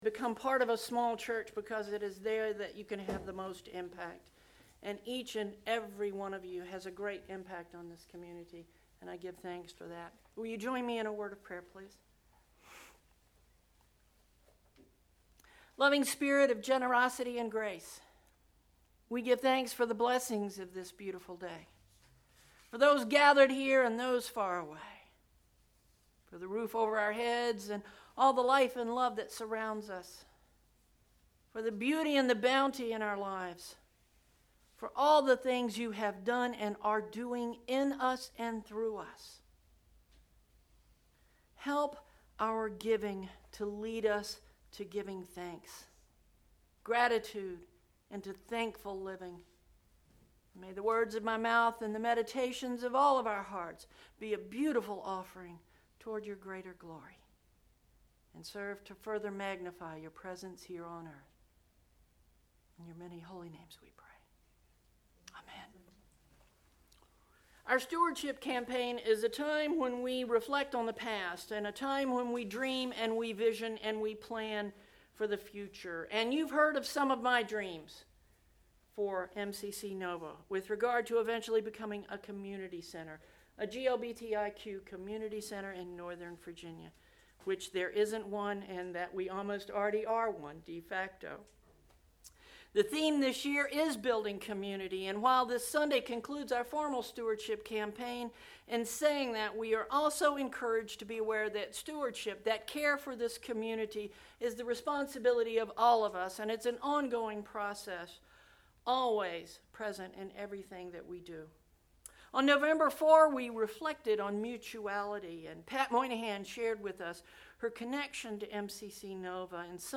click to download 11/25 Sermon “Reflection: Thanksgiving” Preaching